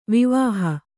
♪ vivāha